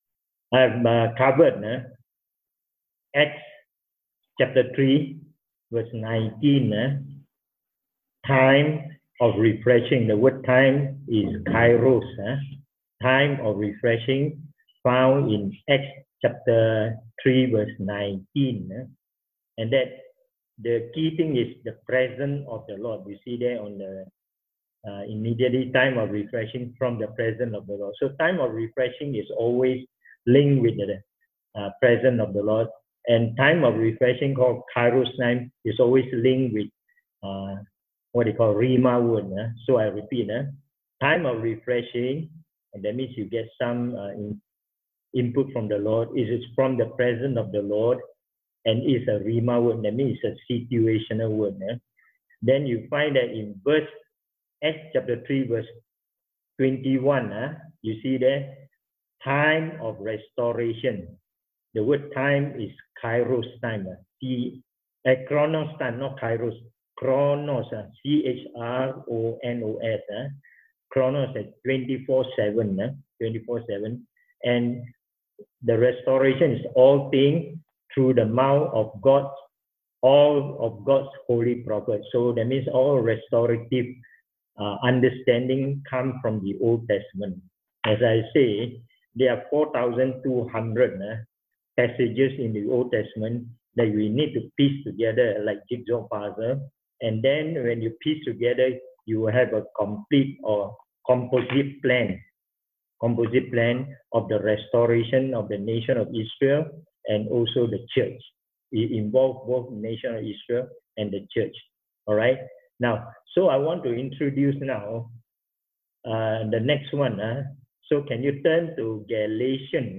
Restoration Teaching (Part 1)Date